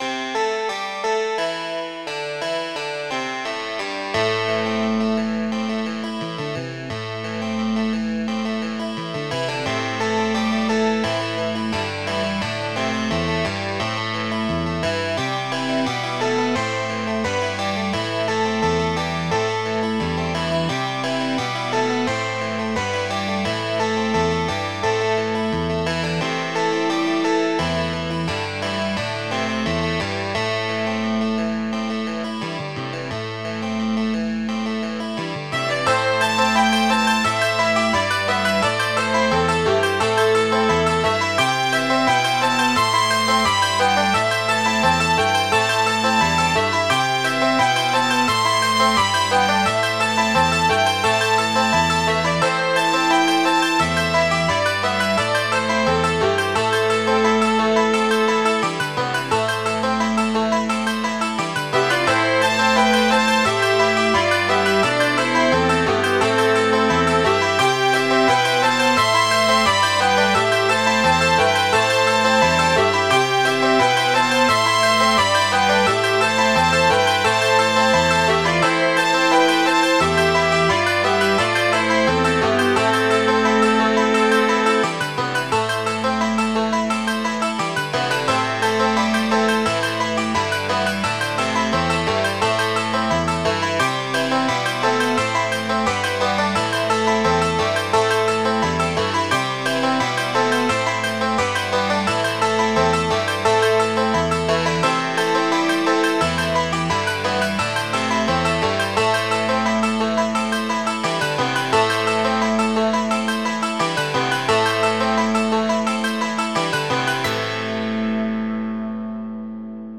Midi File, Lyrics and Information to Unreconstructed Rebel